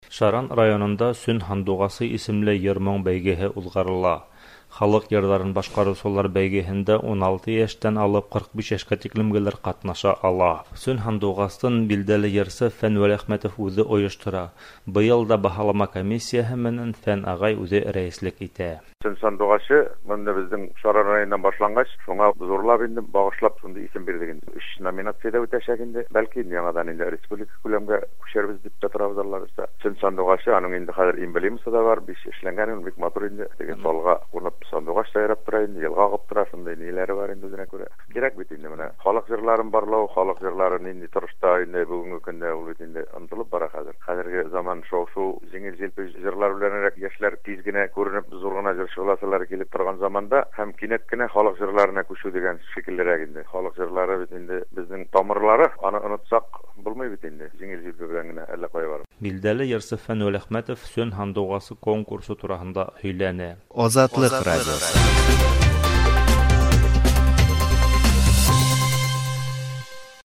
Шаранда "Сөн сандугачы" фестивале узды
"Сөн сандугачы" исемле татар җыры фестивале 4 сентябрь көнне аңа нигез салган Татарстан һәм Башкортстанның халык артисты Фән Вәлиәхмәтовның туган ягы Башкортстанның Шаран районында узды.